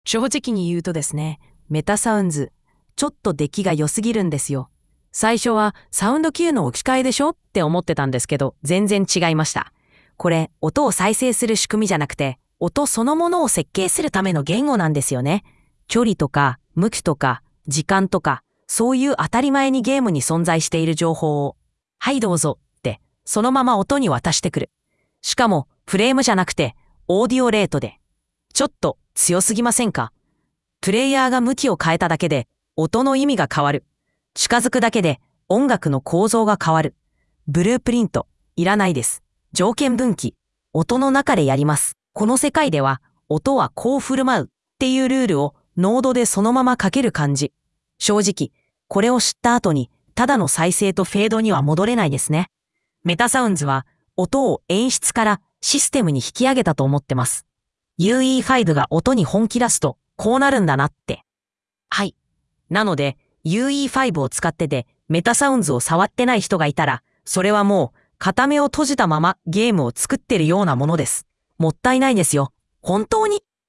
TTS を使って、MetaSounds に喋らせる音声を適当に用意しました。
今回は素材がモノラル音源のため、